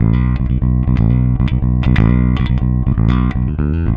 -JP THROB B.wav